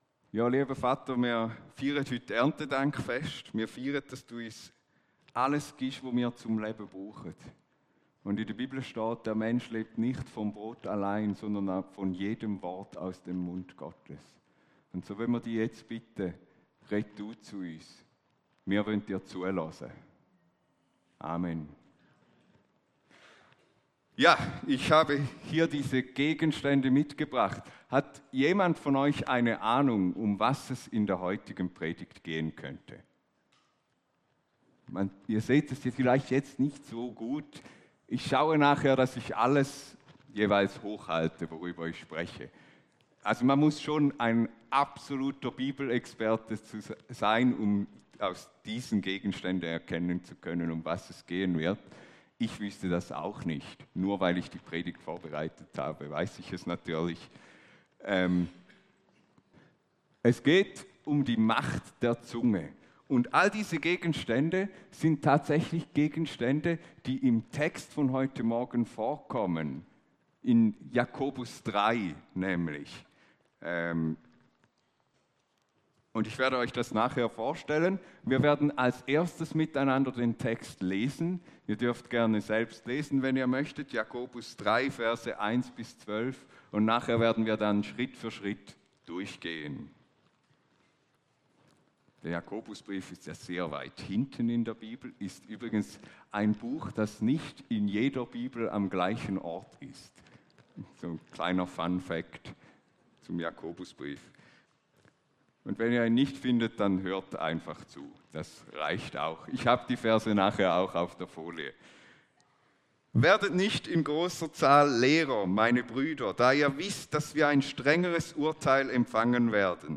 Predigt
Hier hörst du die Predigten aus unserer Gemeinde.